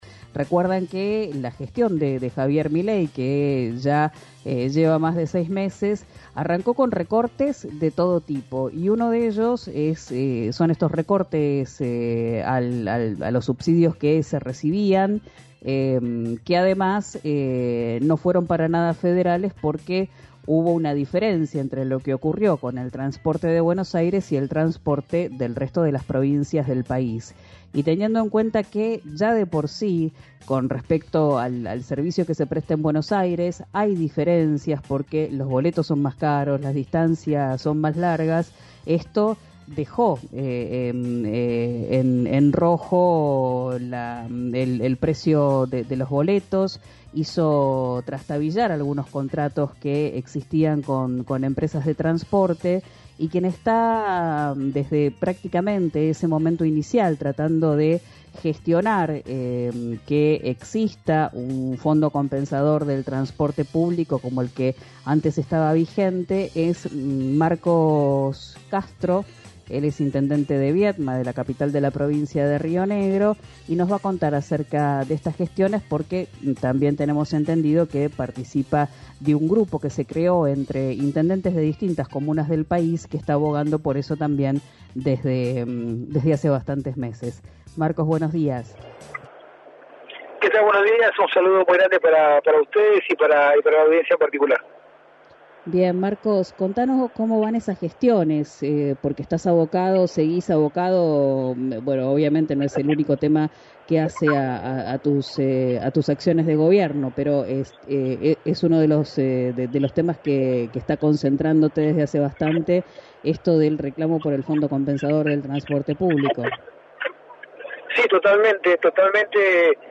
Escuchá al intendente de Viedma, Marcos Castro, por RÍO NEGRO RADIO